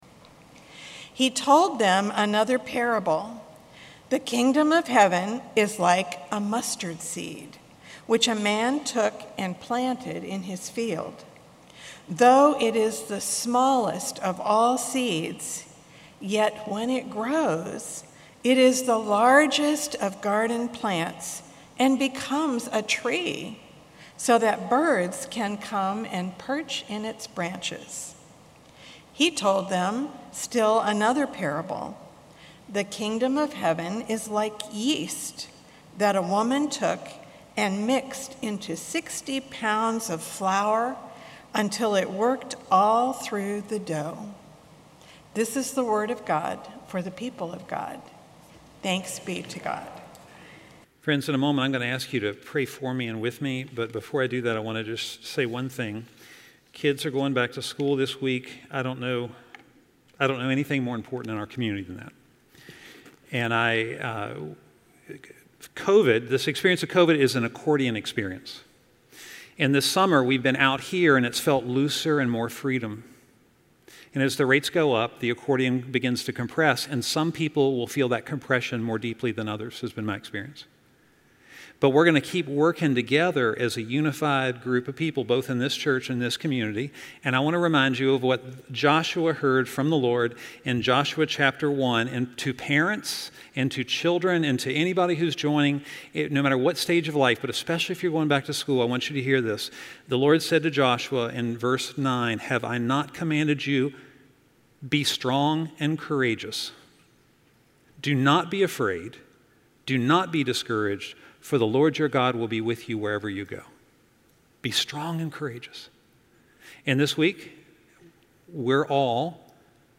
If we want to leave a legacy of stewardship and care for future generations to live and live abundantly then, we need to act now. In this sermon, we will talk about some stories in the Scripture where wonderful legacies were left behind for future generations and how we might do that today.